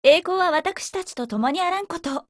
Cv-20403_warcry.mp3